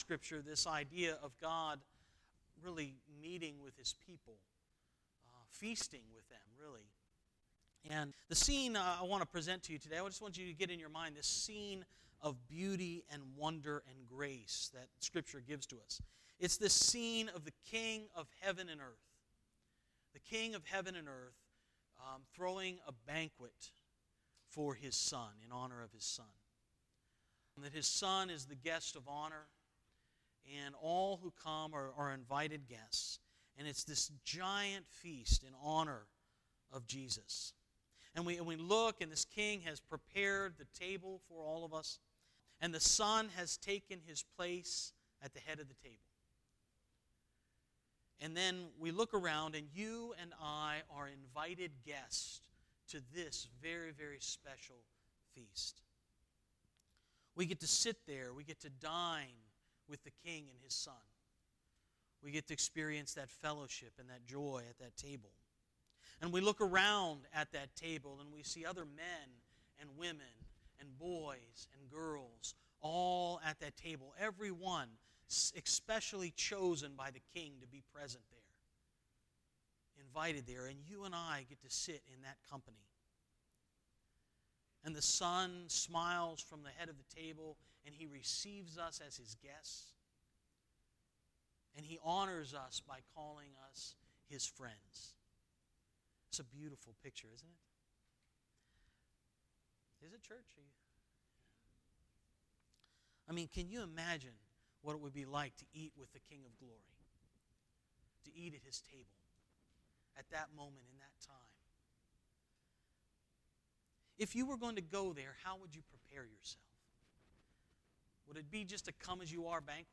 10-14-18 Sermon